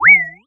pgs/Assets/Audio/Comedy_Cartoon/cartoon_boing_jump_14.wav
cartoon_boing_jump_14.wav